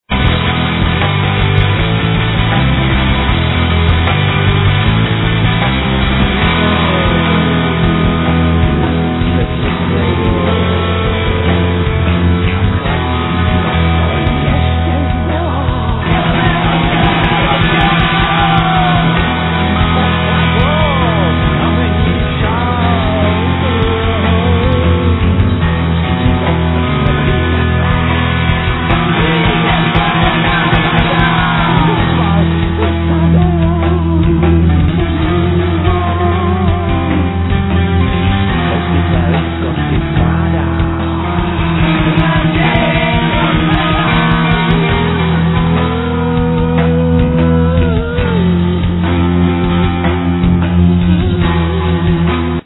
Guitars, Vocals, Percussions, Handclap, Echoes
Bass, Vocals, Acoustic guitar, Piano, Percussions
Drum kit, Vocals, Paino, Percussions, Handclap
Piano, Organ, Percussions, Snap